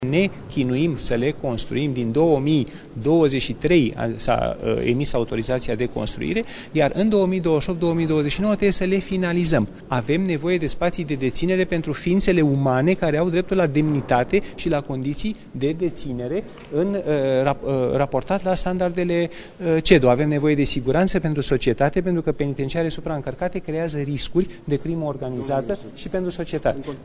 Ministrul Justiției, Radu Marinescu: „Avem nevoie de spații de deținere pentru ființele umane care au dreptul la demnitate”